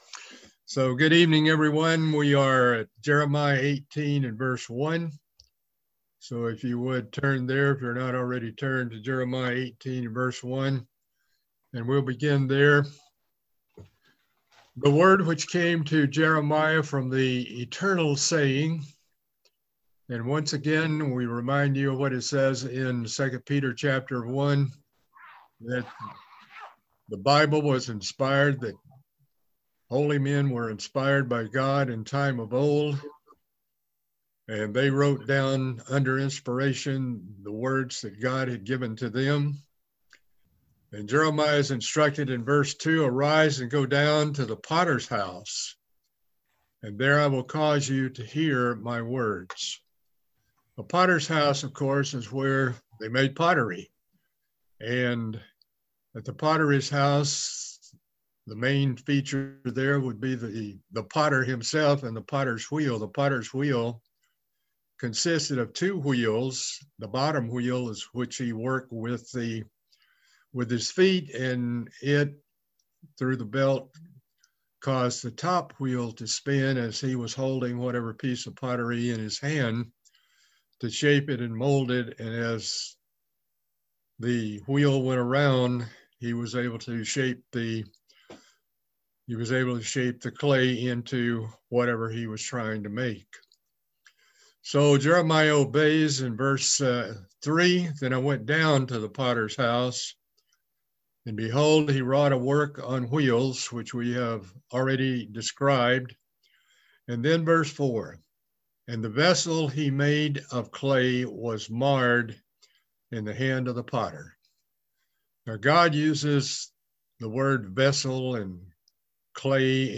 Part 11 of a Bible Study series on the book of Jeremiah